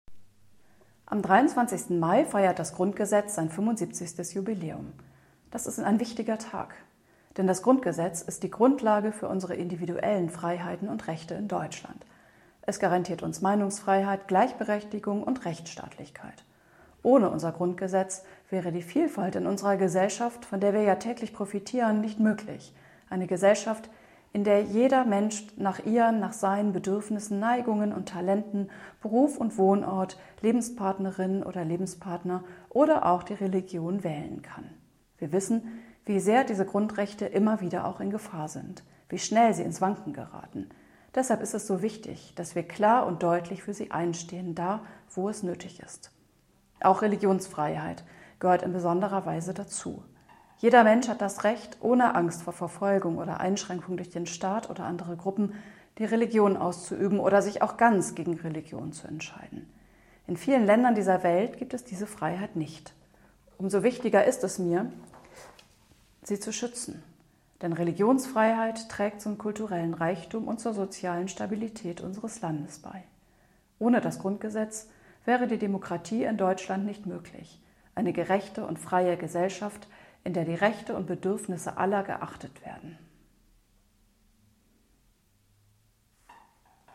Die Bischöfin im Sprengel Schleswig und Holstein der Nordkirche, Nora Steen, weist in einem Statement auf die Bedeutung des Grundgesetztes für die Demokratie in Deutschland hin.
Bischoefin_Steen_zum_Grundgesetz.mp3